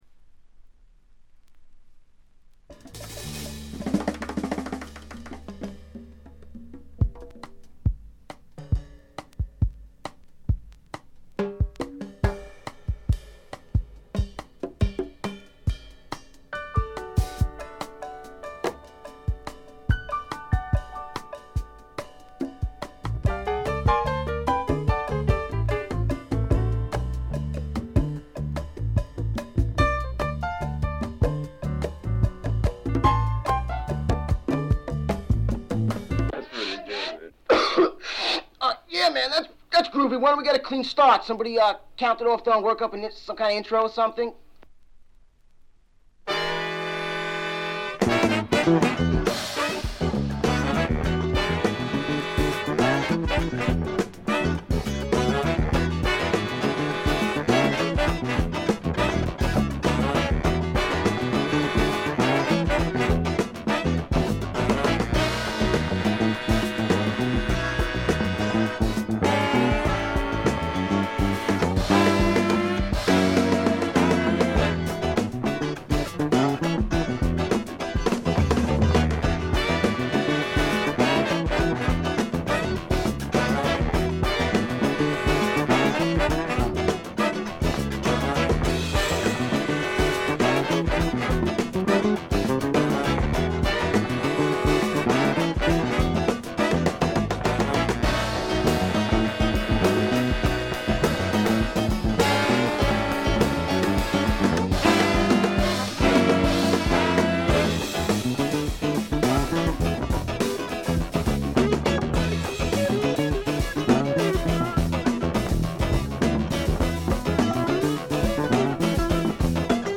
ほとんどノイズ感無し。
よりファンキーに、よりダーティーにきめていて文句無し！
試聴曲は現品からの取り込み音源です。